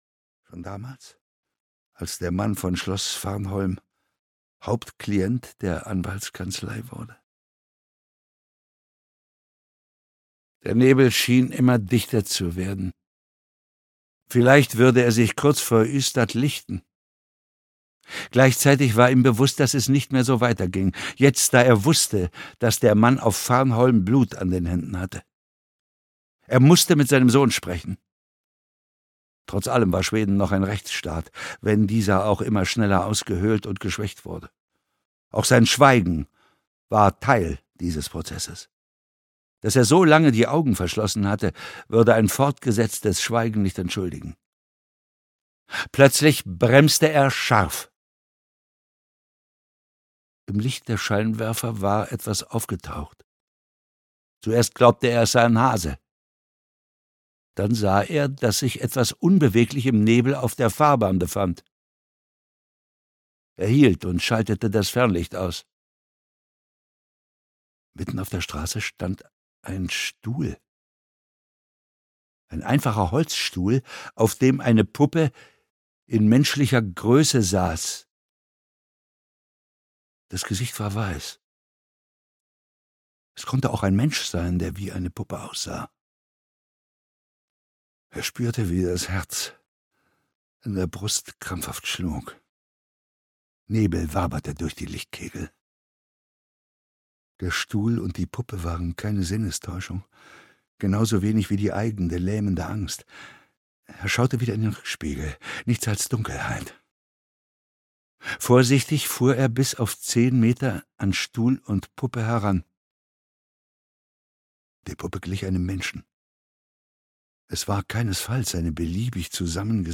Der Mann, der lächelte (Ein Kurt-Wallander-Krimi 5) - Henning Mankell - Hörbuch